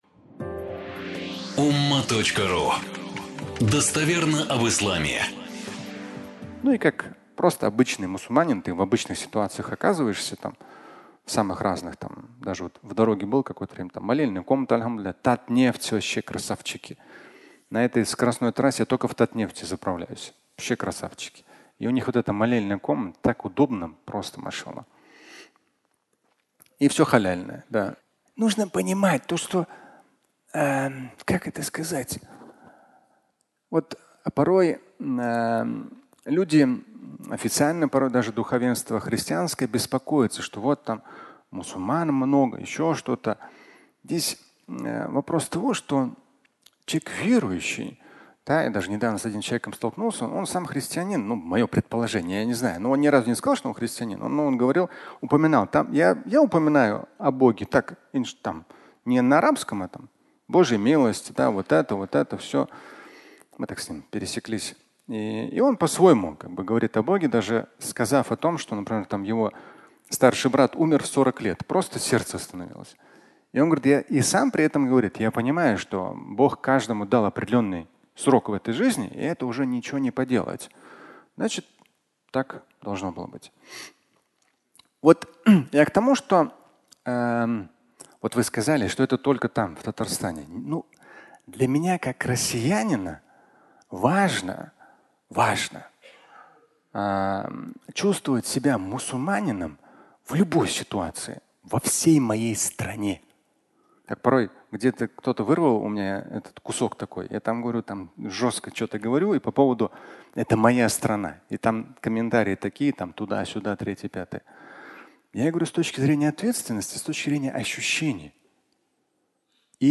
«Татнефть» (аудиолекция)
Фрагмент пятничной лекции